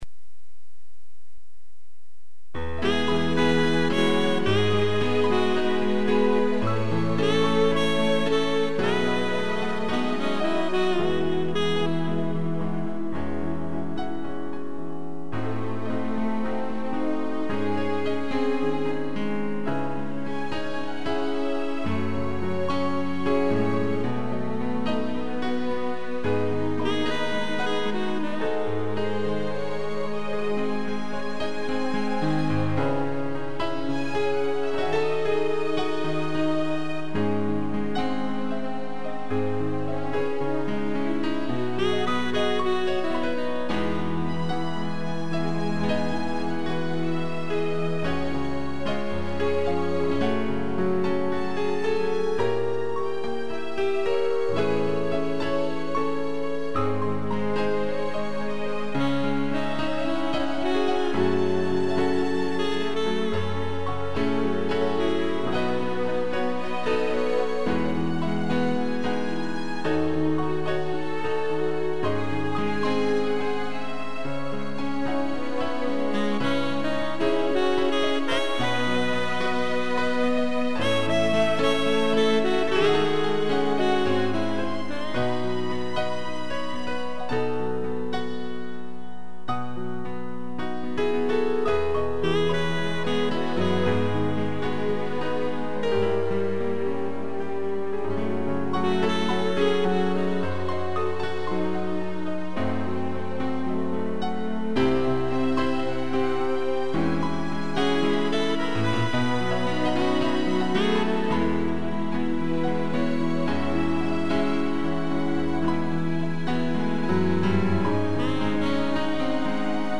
2 pianos, sax e strings
(instrumental)